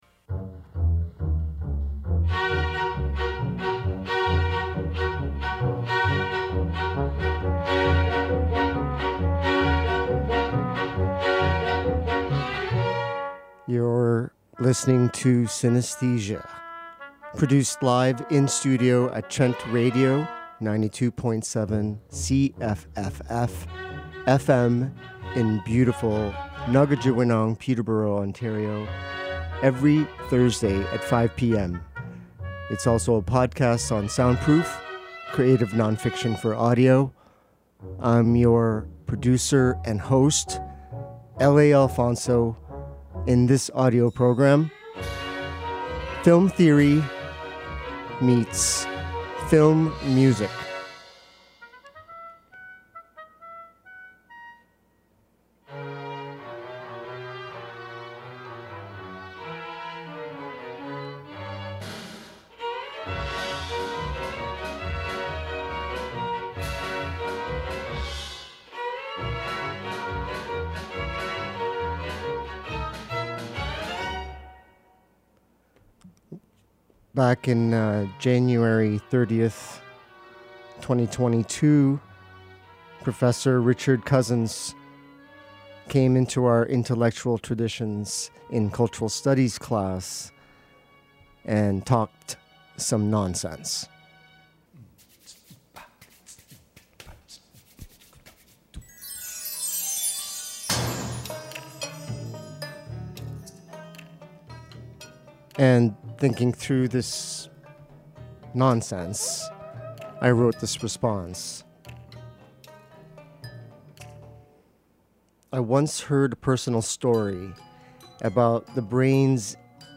Cinesthesia 09 Original Broadcast Radio Playlist March 14, 2024 1.